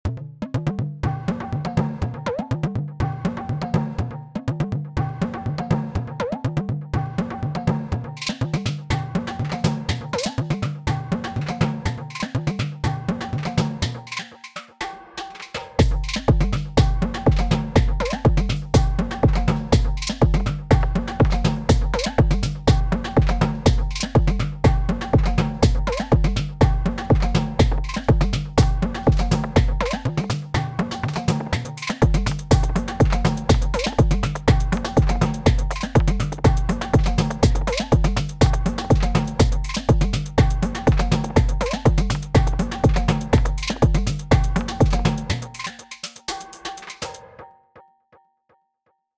Toy Techno made in a rickety shed.